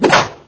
bird_shot.wav